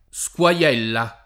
Squaiella [ S k U a L$ lla ] cogn.